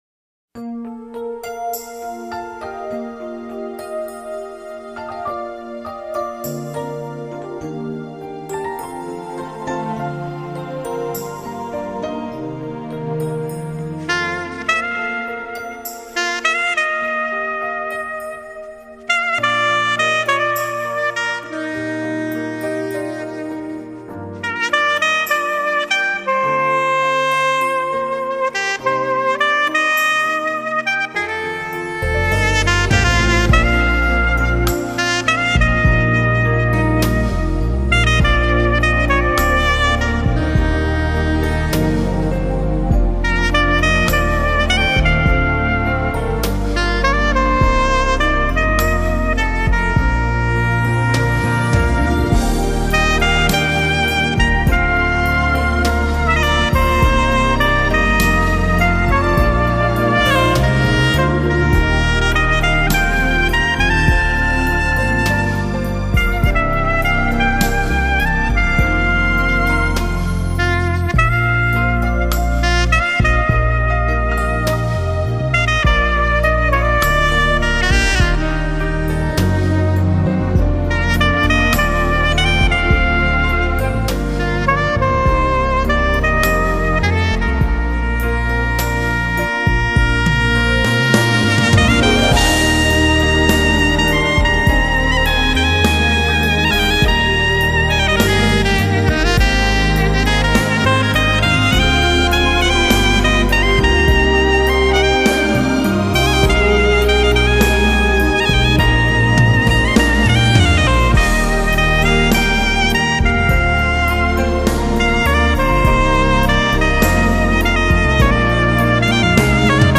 高超华丽的演奏技巧、多元抒情的曲风，这就是优雅、华丽而独特的“吉”式音乐。